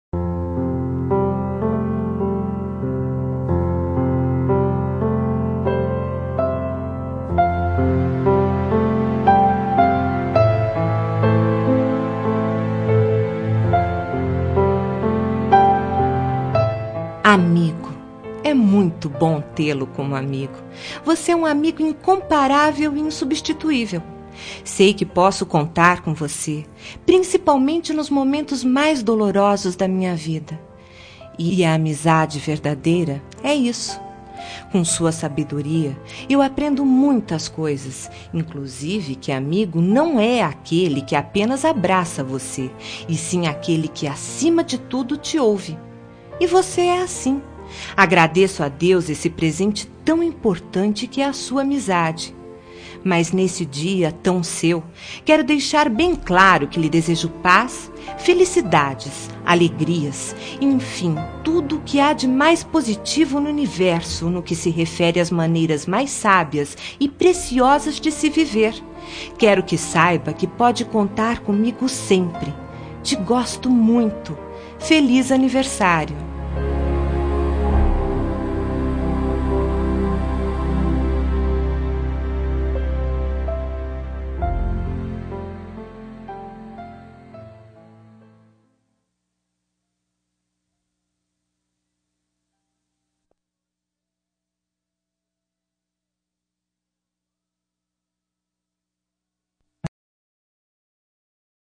Telemensagem de Aniversário de Amigo – Voz Feminina – Cód: 1554